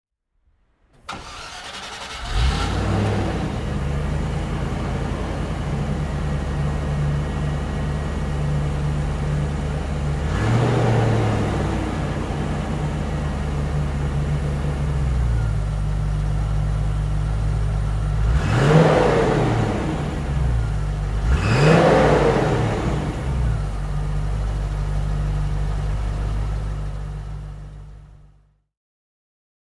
Range Rover SEi Vogue (1990) - Starten und Leerlauf
Range_Rover_1990.mp3